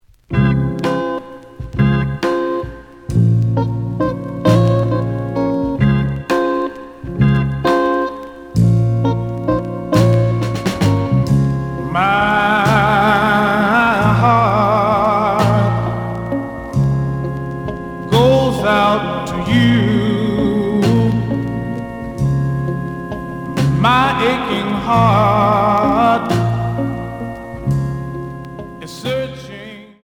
試聴は実際のレコードから録音しています。
●Genre: Soul, 60's Soul
傷は多いが、A面のプレイはまずまず。)